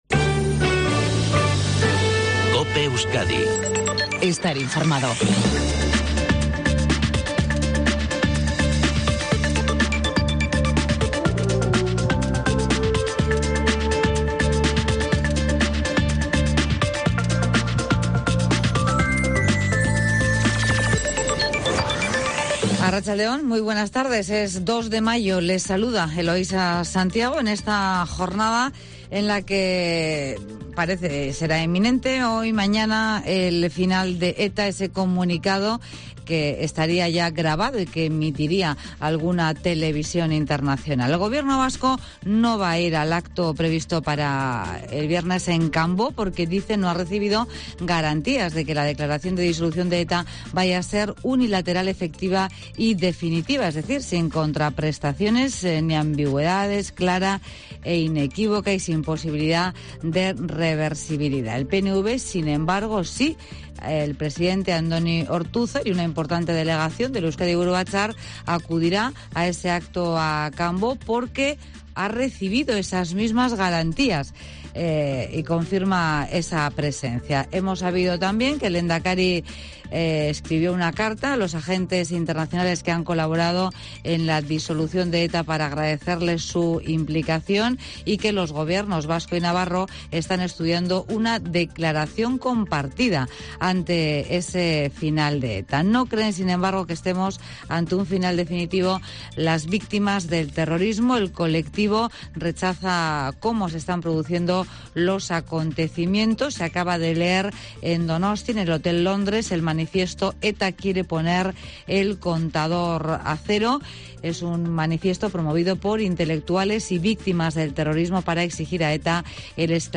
INFORMATIVO MEDIODÍA EUSKADI. 14:20h